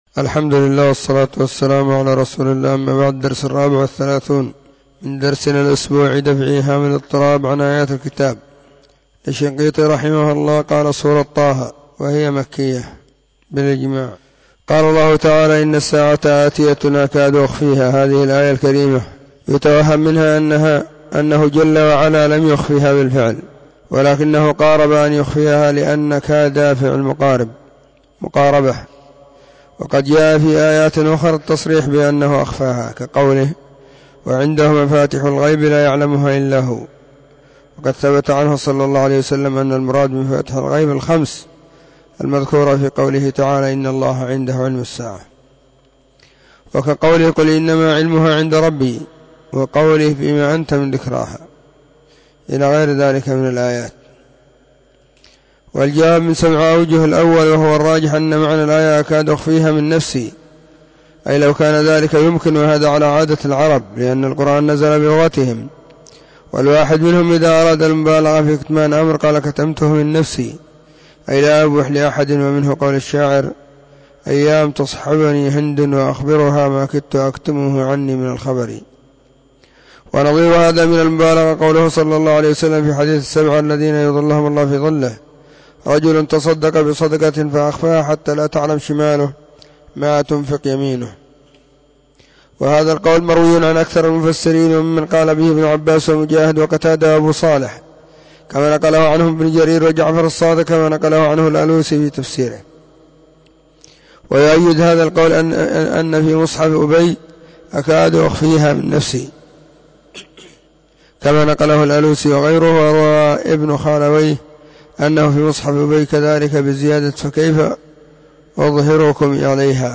⏱ [بعد صلاة الظهر في كل يوم الخميس]
كتاب-دفع-إيهام-الاضطراب-الدرس-34.mp3